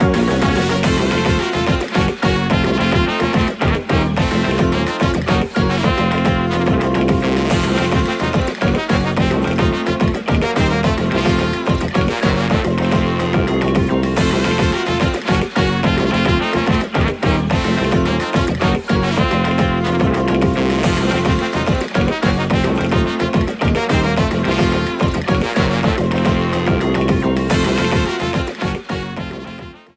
A boss battle theme
Ripped from game
clipped to 30 seconds and applied fade-out